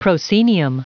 Prononciation du mot proscenium en anglais (fichier audio)
Prononciation du mot : proscenium